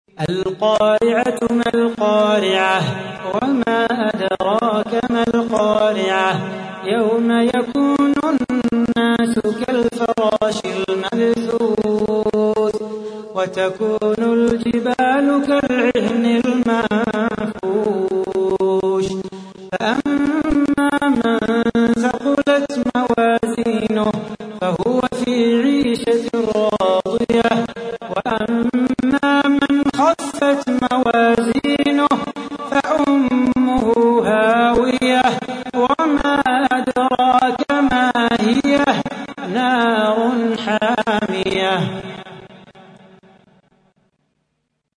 تحميل : 101. سورة القارعة / القارئ صلاح بو خاطر / القرآن الكريم / موقع يا حسين